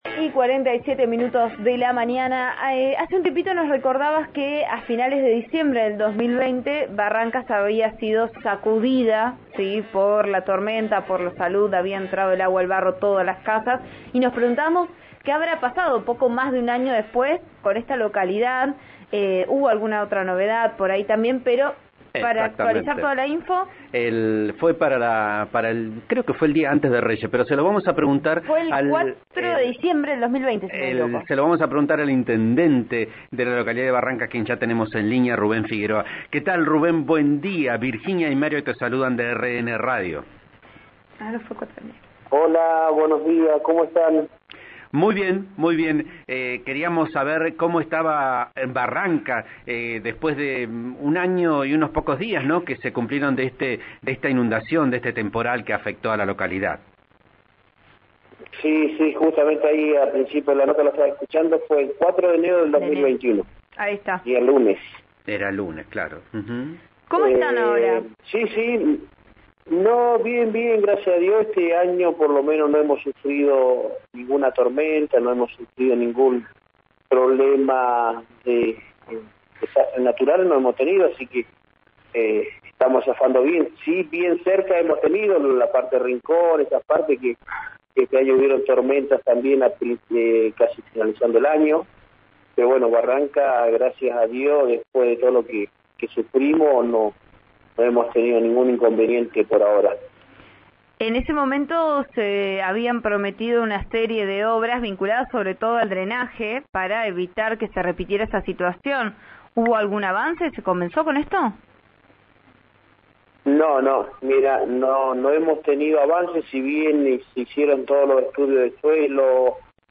El intendente Rubén Figueroa aseguró en «Vos A Diario» (RN RADIO 89.3) que sin los pluvioaluvionales y ante nuevas lluvias fuertes, sufrirían «las mismas consecuencias».